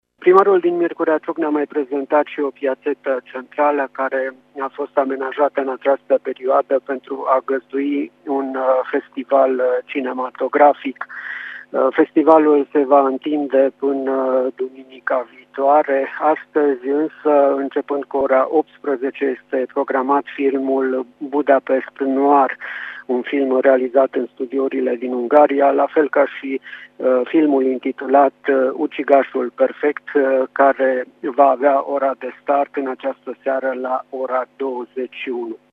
Aflat în Caravana Bicicleta Radio Tg.Mureș, în trecere prin M. Ciuc,